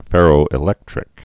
(fĕrō-ĭ-lĕktrĭk)